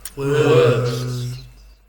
several different recordings of the word "words" layered on top of each other, roughly starting at the same time, some a bit longer than others, in various intonations